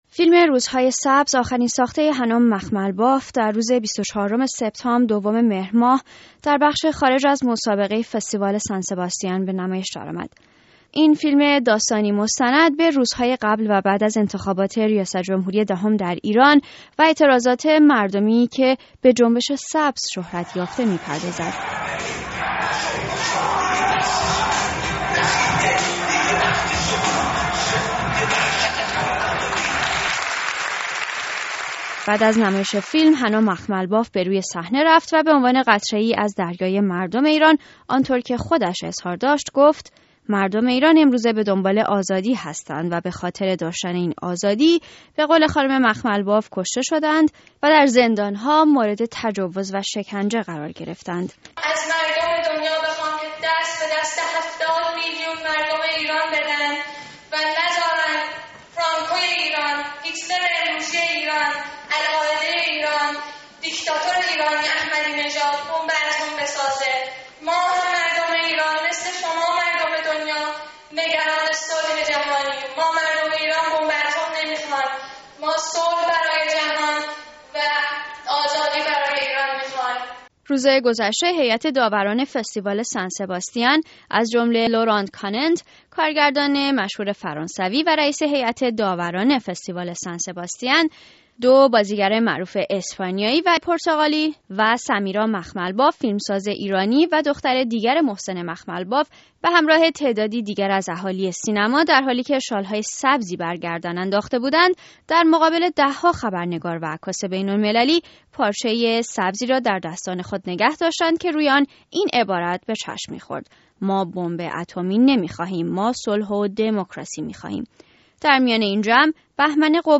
گزارش رادیویی مرتبط